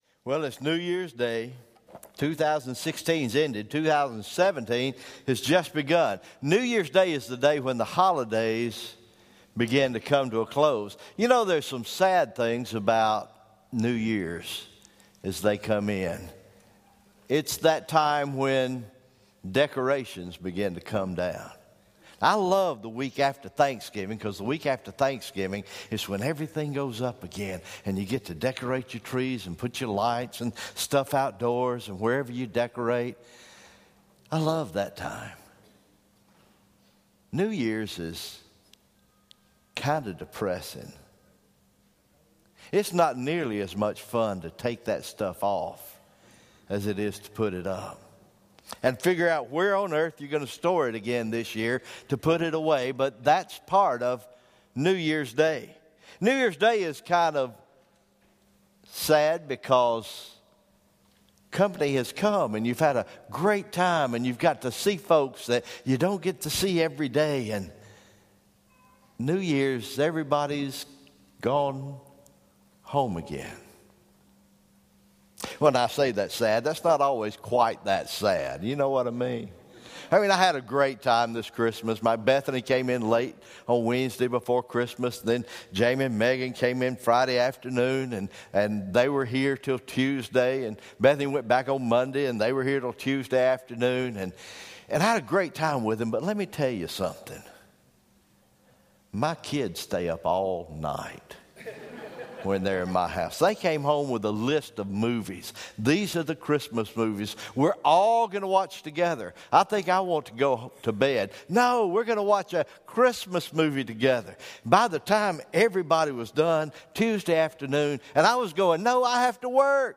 January 1, 2017 Morning Worship